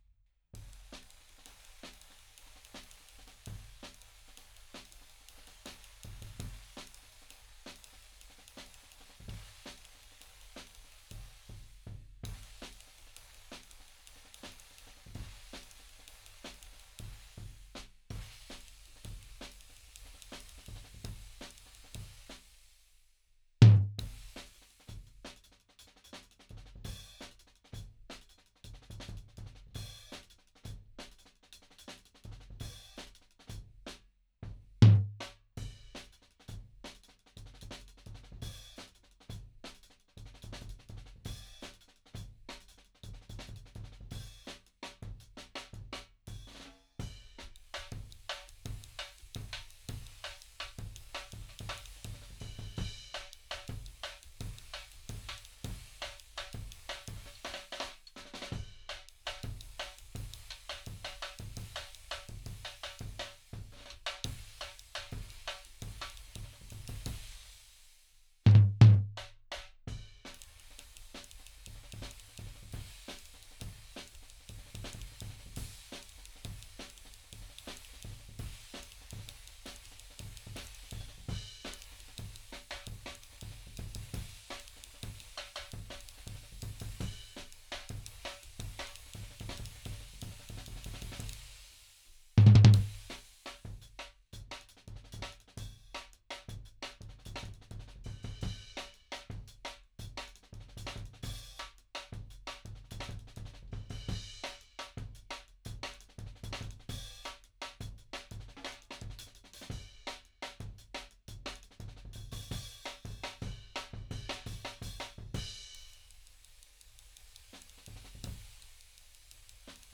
Index of /4 DRUM N BASS:JUNGLE BEATS/BEATS OF THE JUNGLE THAT ARE ANTIFUNGAL!!/RAW MULTITRACKS
FLOOR TOM_1.wav